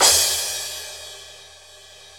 Drums/CYM_NOW! Cymbals